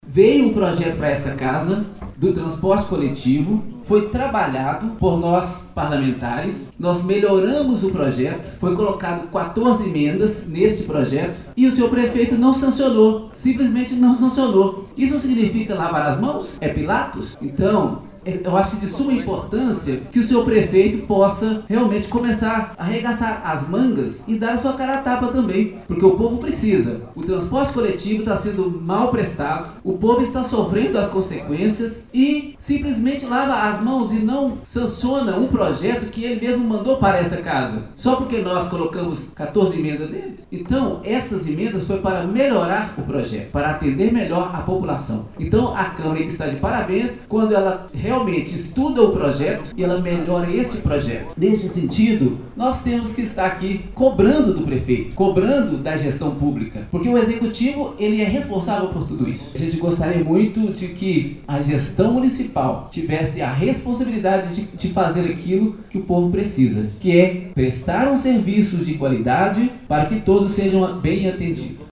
Mário Justino da Silva (PRB), secretário da mesa diretora da Câmara Municipal, fez uso da tribuna livre e criticou a ação do prefeito Elias Diniz (PSD).